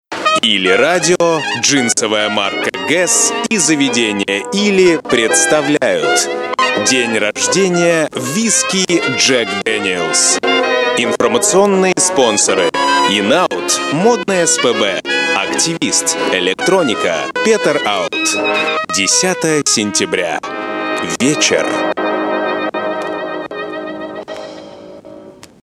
БЕЗ ГОВОРА. БЕЗ ДУРАЦКИХ ИНТОНАЦИЙ.